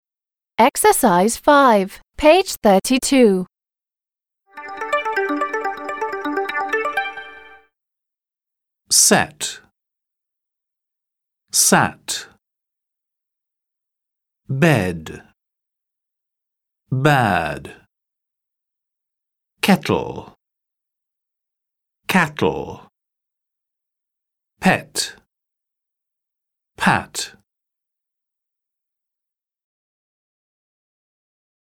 Listen and repeat.